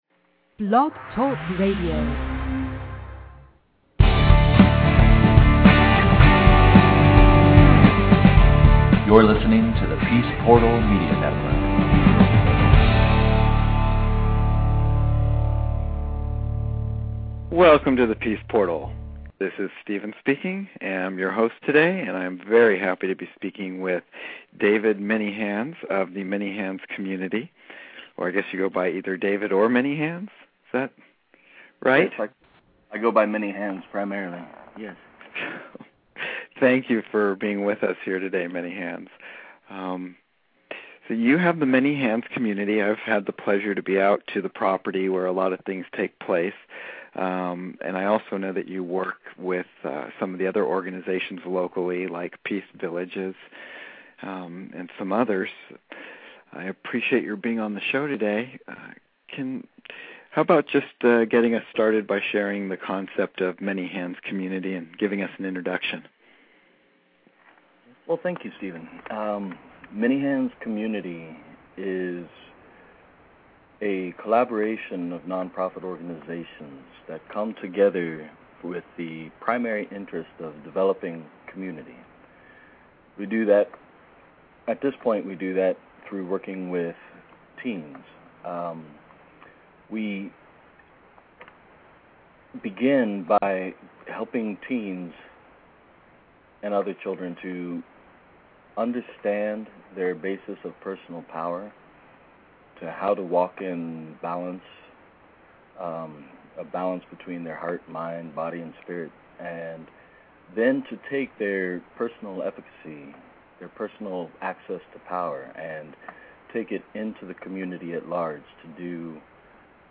MHC Interview
Peace Portal Interview